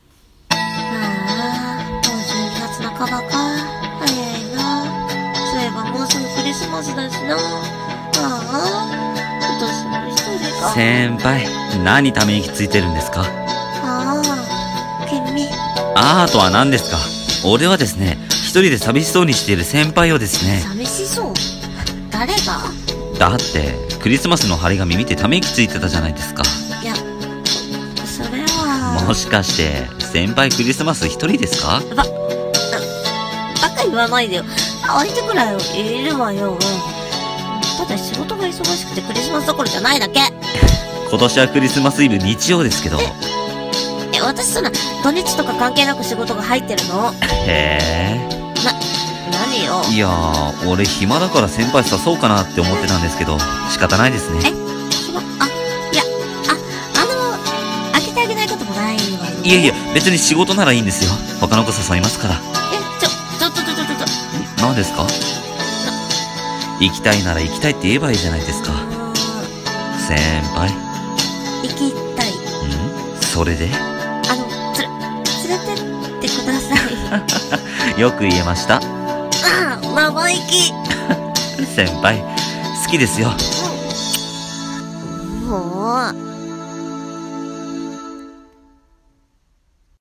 クリスマス・イブ 【声劇】